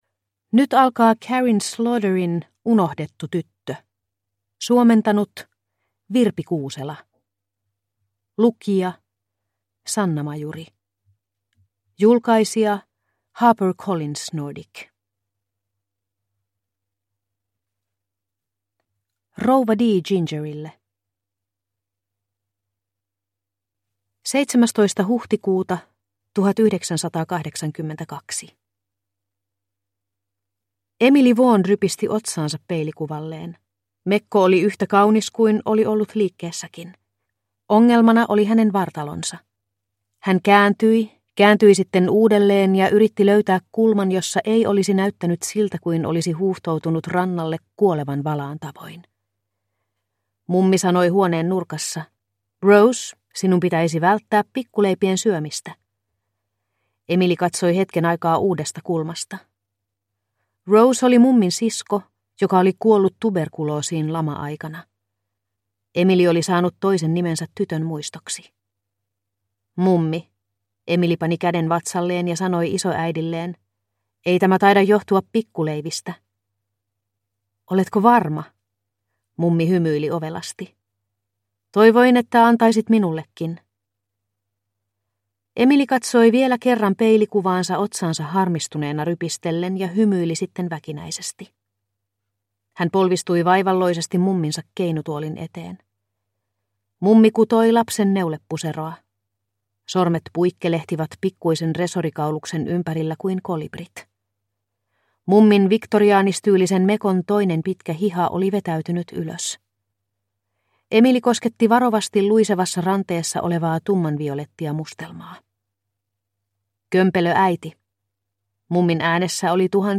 Unohdettu tyttö – Ljudbok – Laddas ner